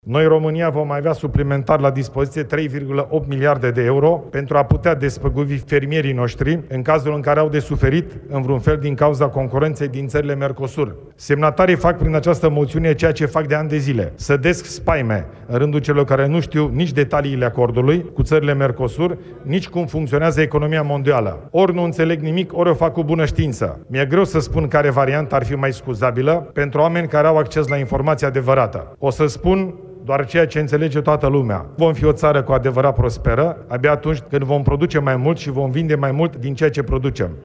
Premierul Ilie Bolojan: „Vom avea suplimentar la dispoziție 3,8 miliarde de euro pentru a putea despăgubi fermierii noștri în cazul în care au de suferit în vreun fel”